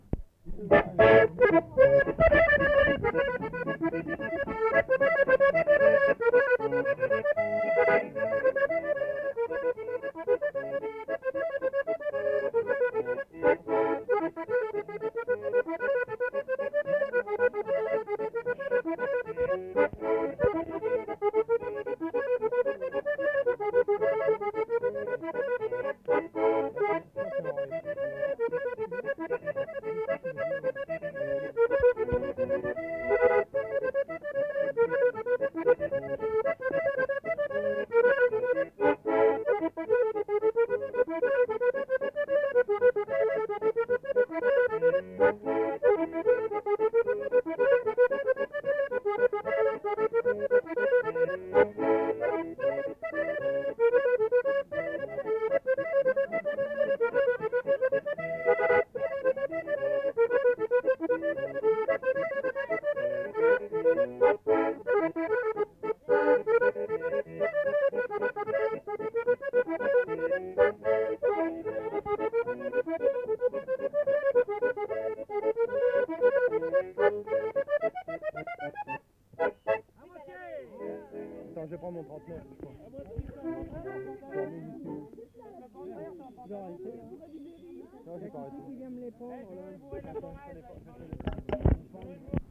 Bourrée